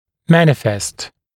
[‘mænɪfest][‘мэнифэст]проявляться, обнаруживаться